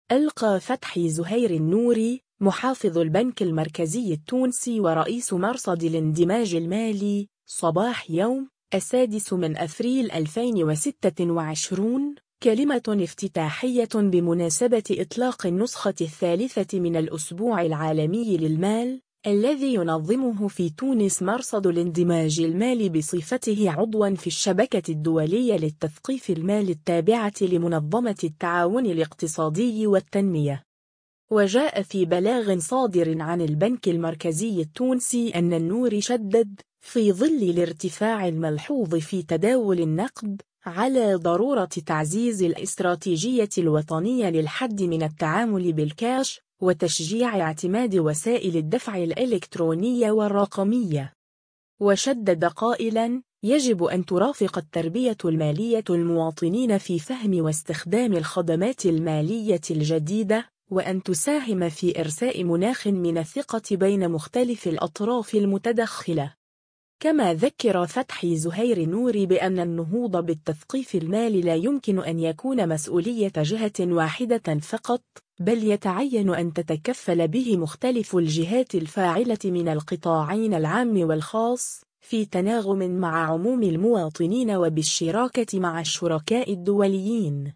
ألقى فتحي زهير النوري، محافظ البنك المركزي التونسي ورئيس مرصد الاندماج المالي، صباح يوم، 6 أفريل 2026، كلمة افتتاحية بمناسبة إطلاق النسخة الثالثة من الأسبوع العالمي للمال، الذي ينظمه في تونس مرصد الاندماج المالي بصفته عضوا في الشبكة الدولية للتثقيف المالي التابعة لمنظمة التعاون الاقتصادي والتنمية.